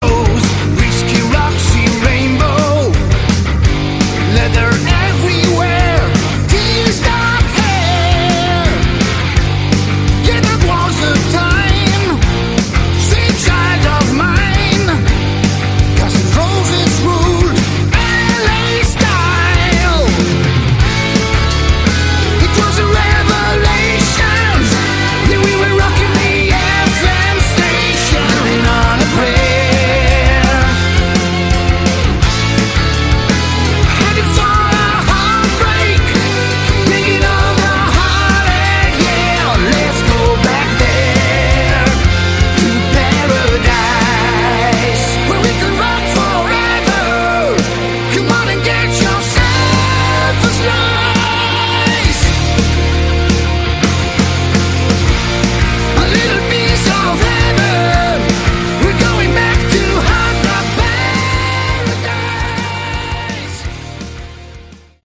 Category: Hard Rock
vocals
guitars
bass
drums
keyboards